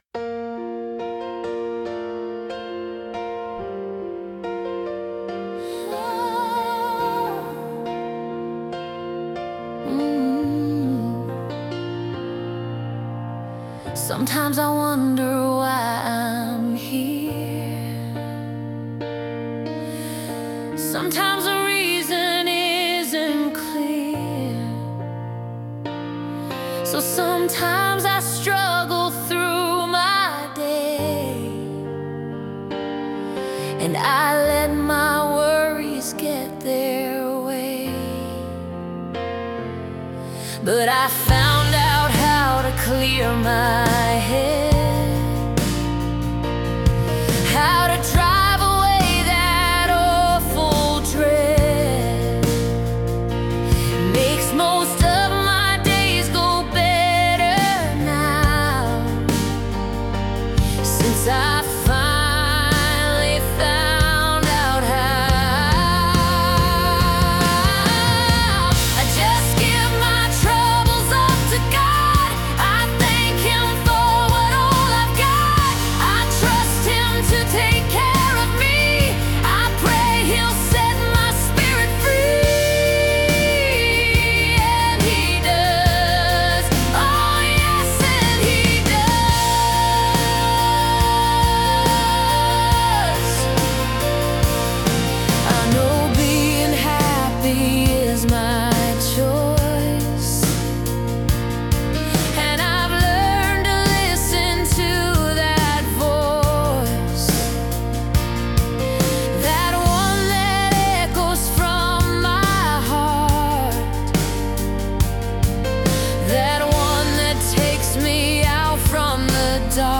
Complete Christian Song
Complete Demo Song, with lyrics and music